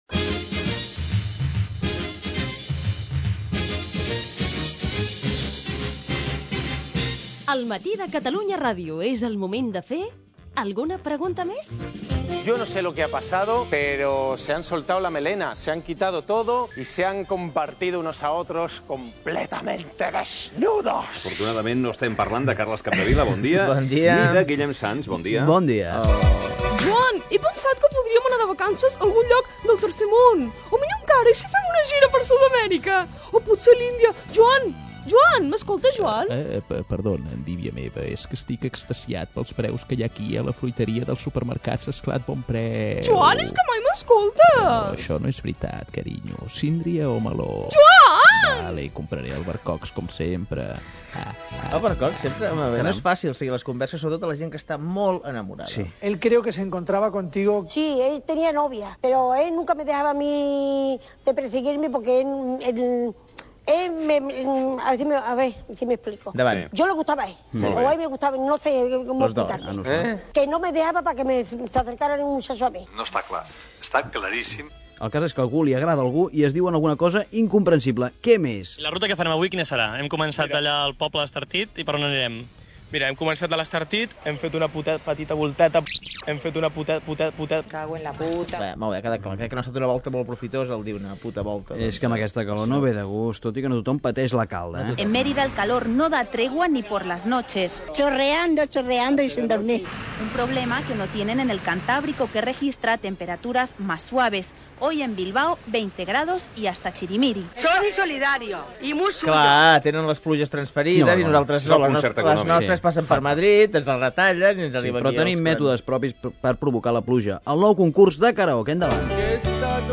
Espai "Alguna pregunta més?" Careta de l'espai i fragments radiofòncis relacionats amb les vagues al transport, l'alcalde de Barcelona Joan Clòs.
Info-entreteniment